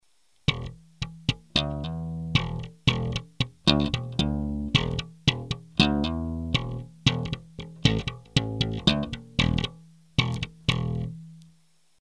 Треки записывал напрямую в линейный вход звуковой карточки без какой либо текущей и последующей обработки звука.
Slap track 4
Треки 4, 5 записаны при положении регулировок "High" на максимуме, "Bass" - на минимуме; 4-й трек - при включенном "Bright", 5-й - при выключенном.
slap_track4.mp3